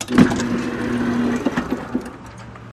Electric Door, Grocery, Open